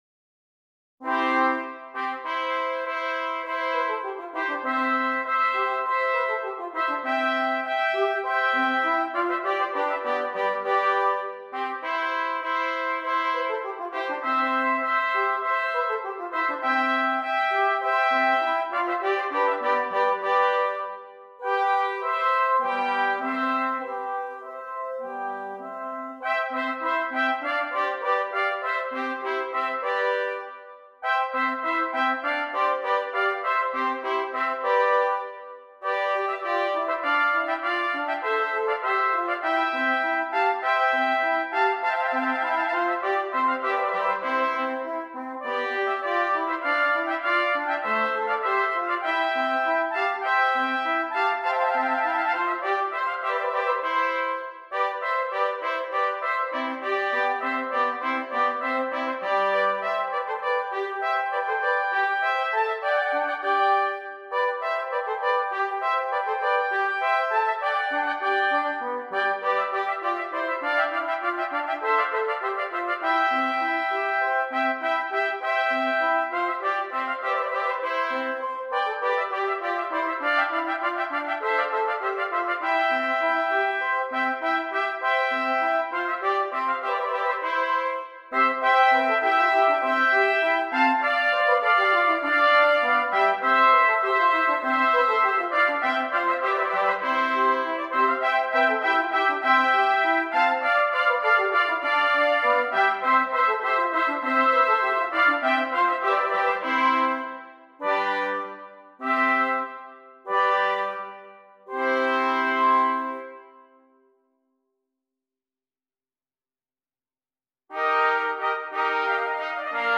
6 Trumpets
trumpet ensemble setting